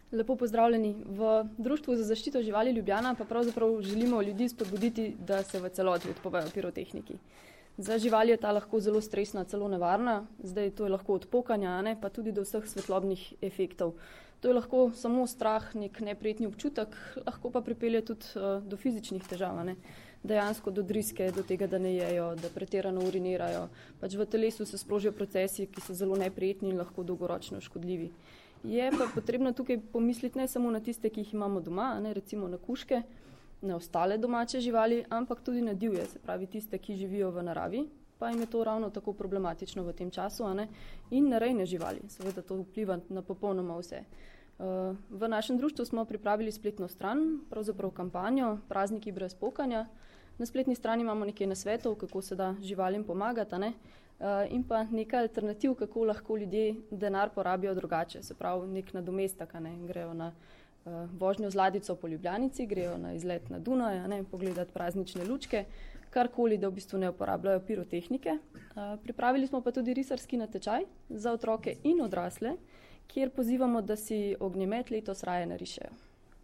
Letošnjo akcijo Bodi zvezda, ne meči petard, pa tudi različne vidike uporabe pirotehnike ter nevarnosti in neprijetnosti, ki jih ta lahko povzroča, so na današnji novinarski konferenci predstavili: